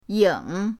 ying3.mp3